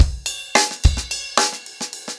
Framework-110BPM_1.1.wav